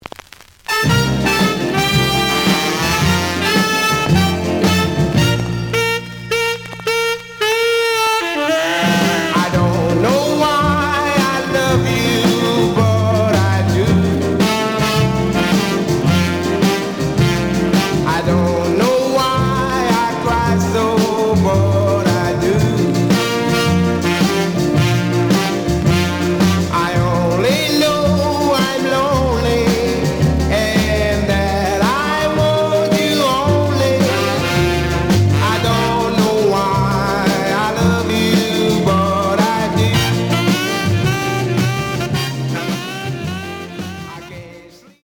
試聴は実際のレコードから録音しています。
●Genre: Rhythm And Blues / Rock 'n' Roll
●Record Grading: G+ (両面のラベルにダメージ。盤に若干の歪み。傷は多いが、A面のプレイはまずまず。)